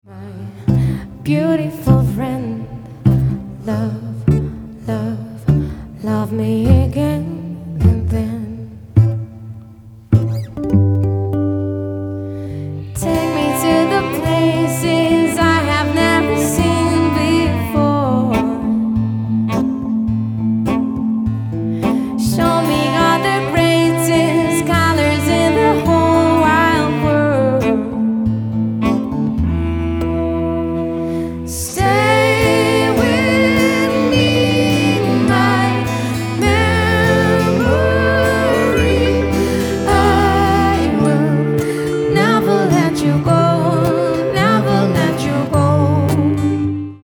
Cello
Marimba, Percussion